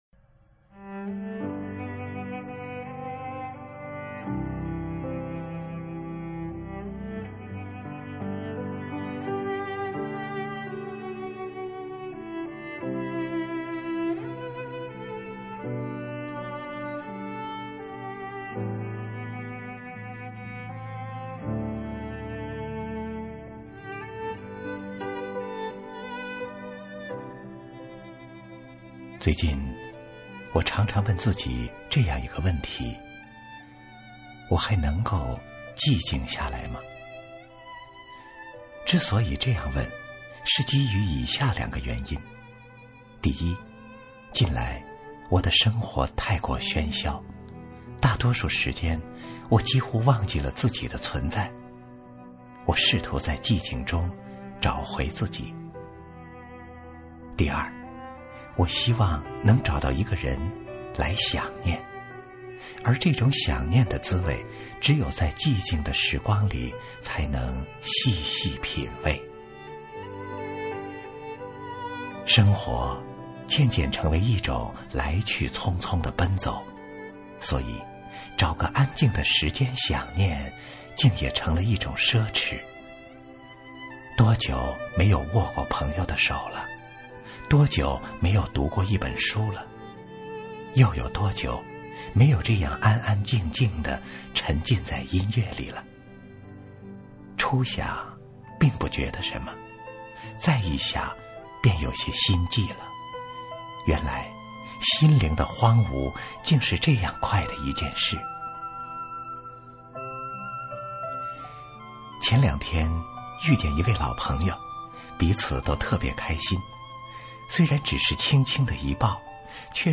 经典朗诵欣赏